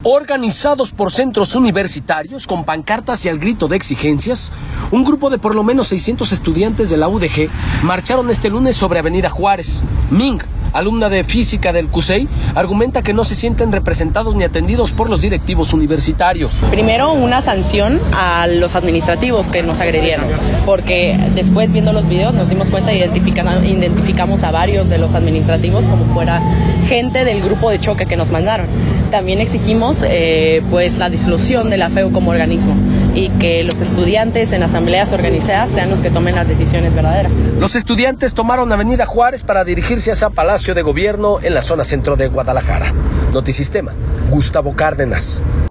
Organizados por centros universitarios, con pancartas y al grito de exigencias, un grupo de por lo menos 600 estudiantes de la UdeG marcharon este lunes sobre avenida Juárez.